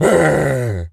gorilla_angry_01.wav